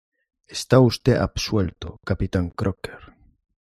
Read more captain (leader) Frequency A1 Hyphenated as ca‧pi‧tán Pronounced as (IPA) /kapiˈtan/ Etymology Borrowed from Late Latin capitāneus, from caput (“head”) + -āneus.